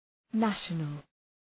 Προφορά
{‘næʃənəl}